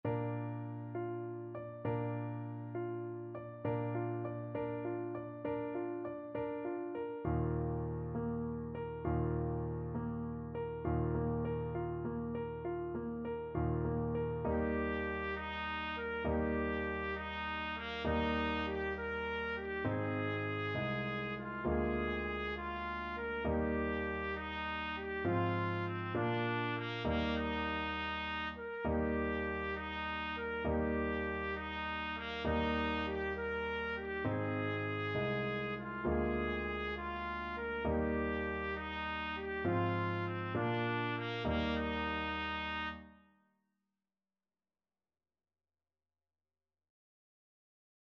Classical Beethoven, Ludwig van Shepherd's Song (from Symphony No.6) Trumpet version
Trumpet
6/8 (View more 6/8 Music)
Allegretto
Eb major (Sounding Pitch) F major (Trumpet in Bb) (View more Eb major Music for Trumpet )
Classical (View more Classical Trumpet Music)